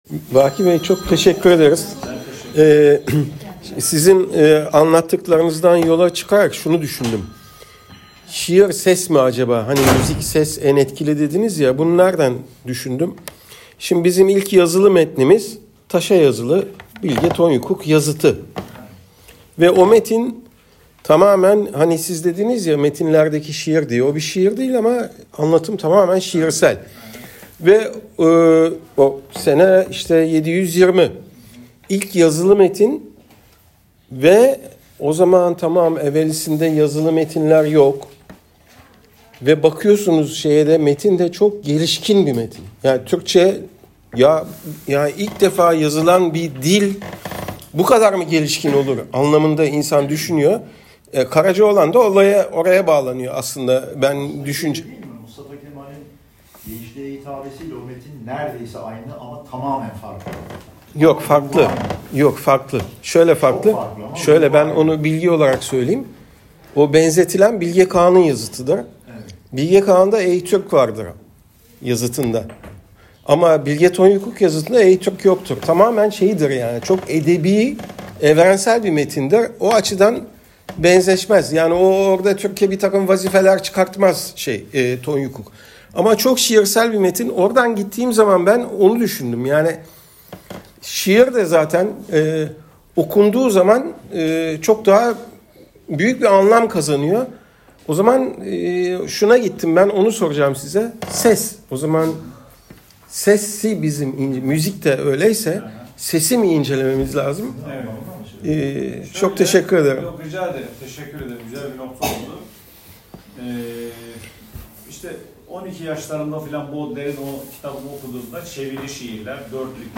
Şiir Akşamı